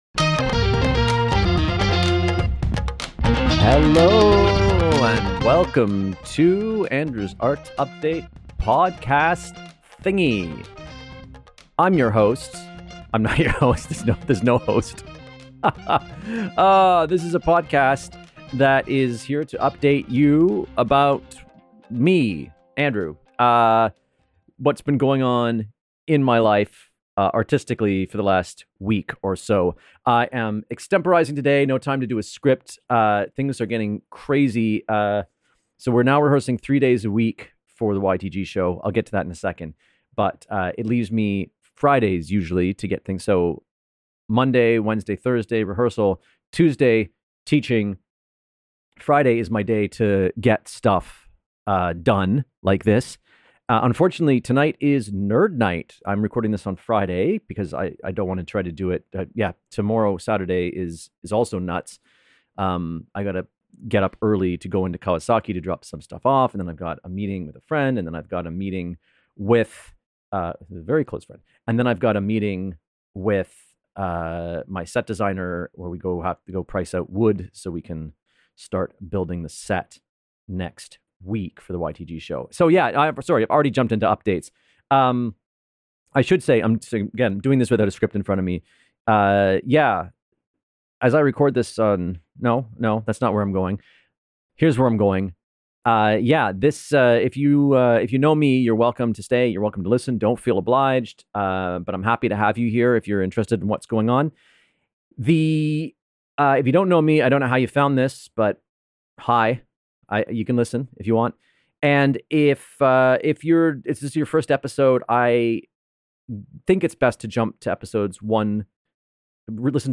This is completely extemporized this week. No time to script anything. I give updates about YTG's upcoming Oblique, mostly.